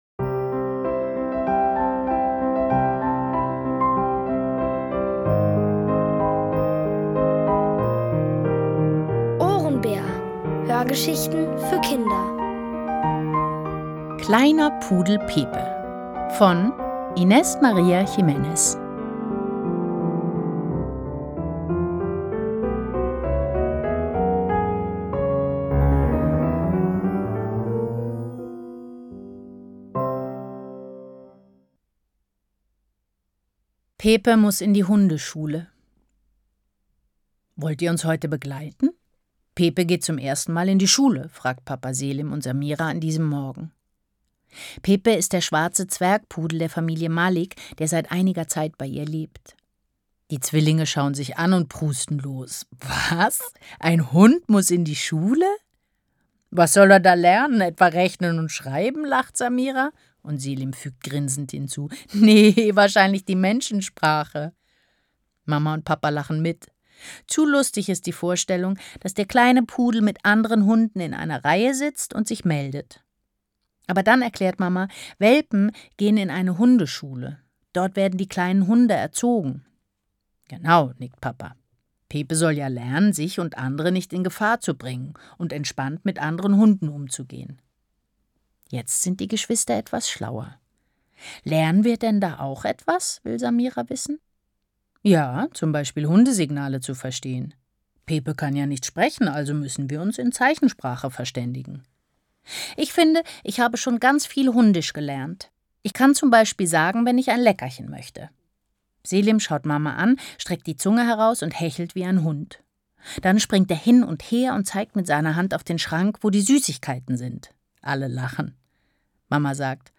OHRENBÄR – das sind täglich Hörgeschichten für Kinder zwischen 4 und 8 Jahren. Von Autoren extra für die Reihe geschrieben und von bekannten Schauspielern gelesen.